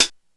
HihatCl.wav